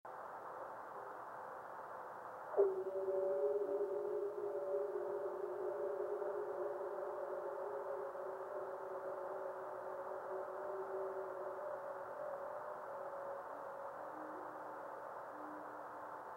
Sentinel capture video missed it but secondary video and forward scatter system recorded it in movie below.
61.250 MHz right channel and 83.250 MHz left channel